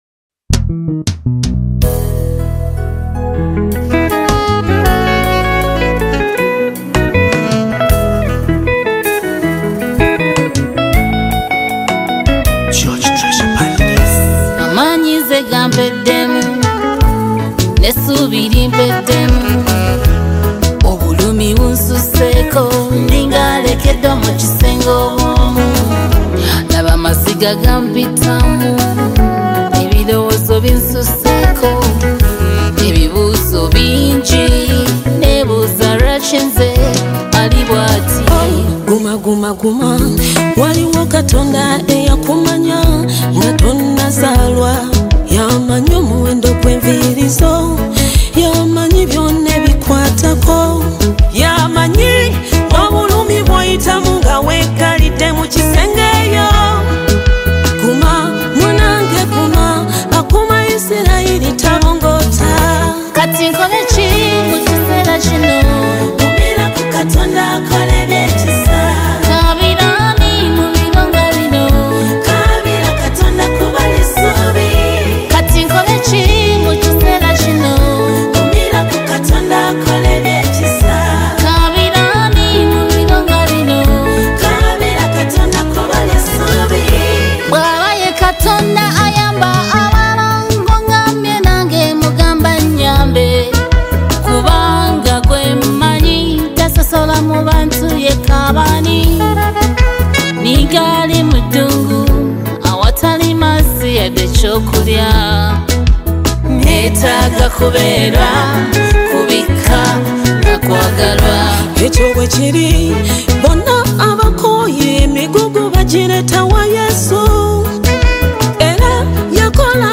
Genre: Gospel Music